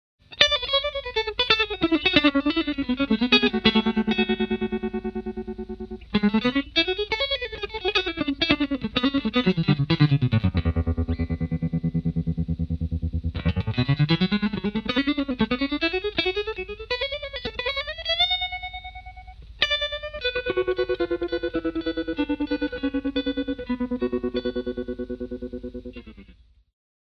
Vintage style optical tremolo
This makes clean and noiseless tremolo.
Demo with Single Pickup